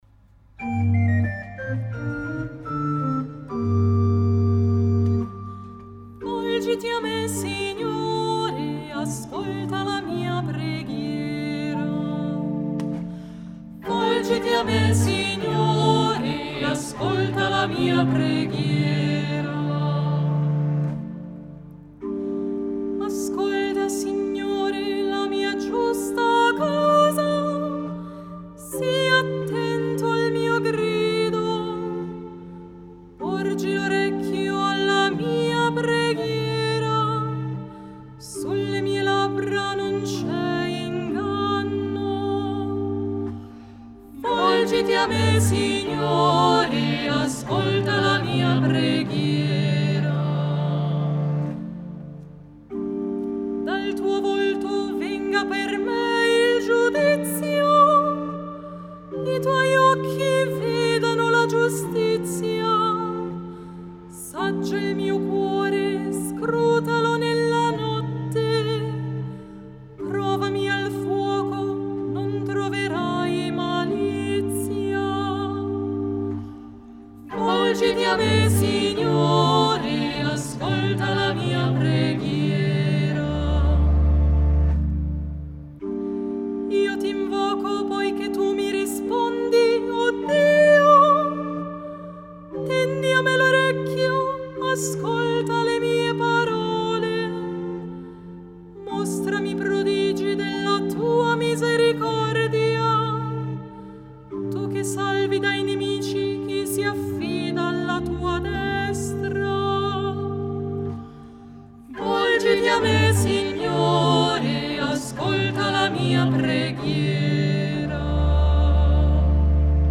Audio Esecuzione a cura dell’Ensemble “Sicut in caelo”